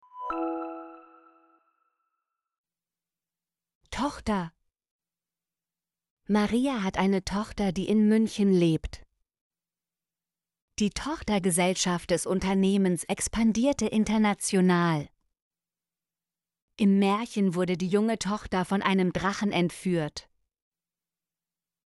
tochter - Example Sentences & Pronunciation, German Frequency List